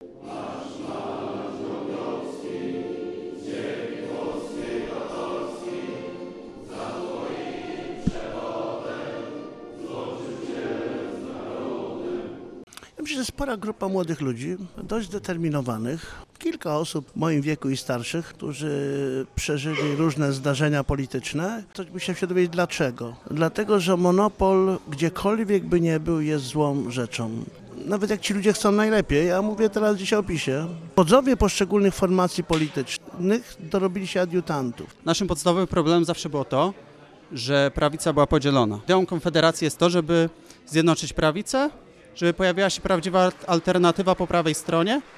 Wojciecha Mojzesowicza zapytaliśmy o powody przyłączenia się do tego ugrupowania.
Bydgoska konwencja wyborcza Konfederacji WiN.